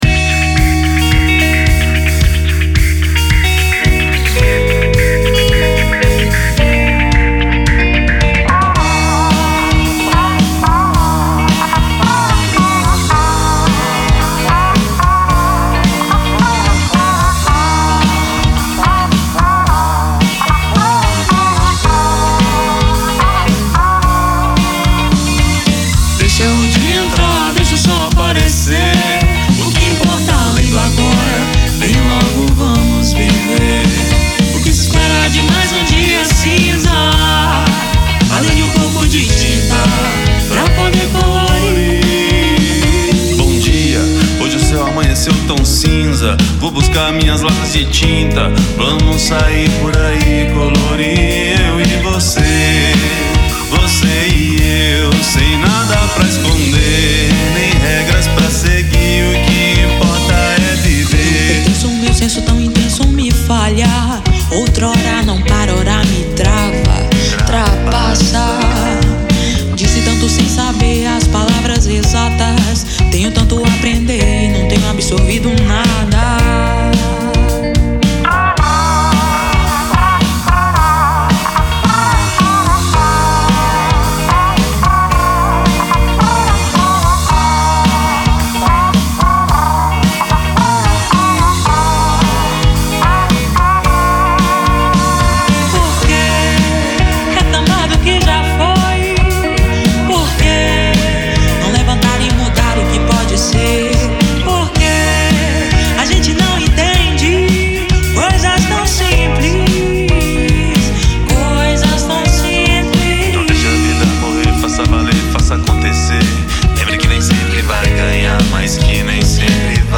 EstiloBlack Music